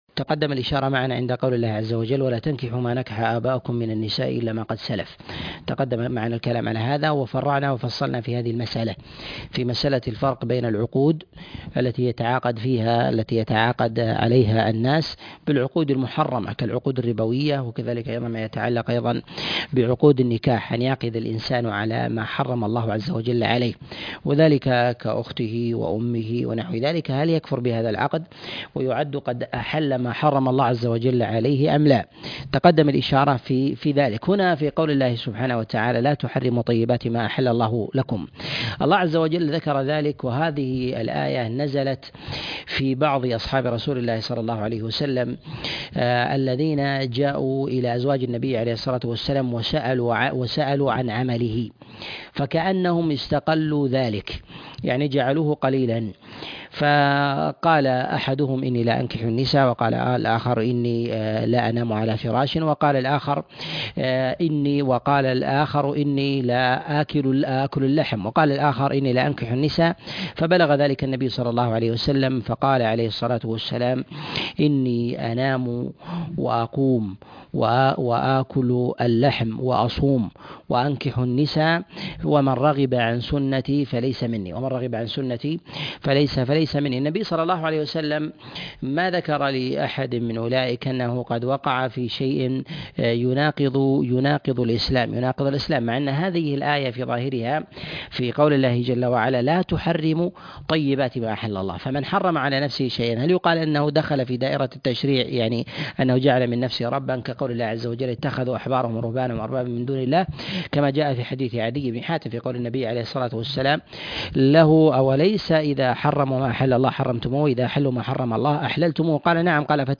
تفسير سورة المائدة 8 - تفسير آيات الأحكام - الدرس التاسع والثمانون